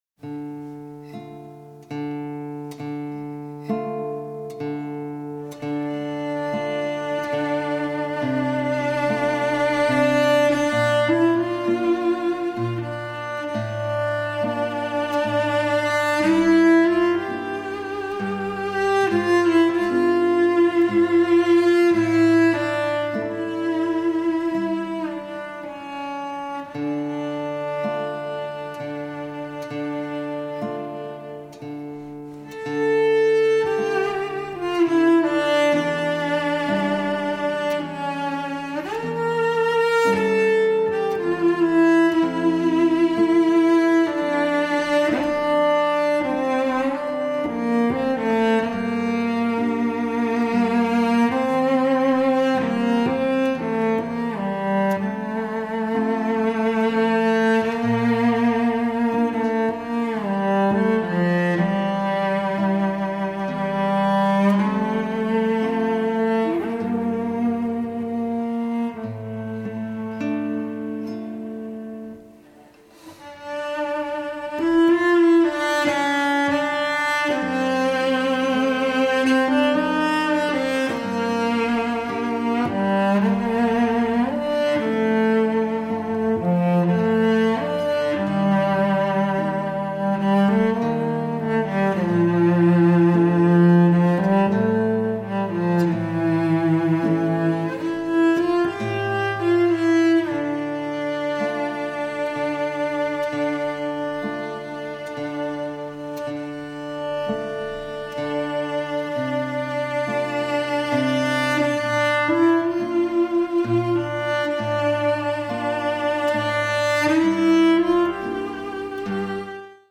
★ 吉他與大提琴的完美珍稀組合，展現悠揚樂韻！
★ 豐富飽滿的共鳴、清脆透明的絕佳音響效果！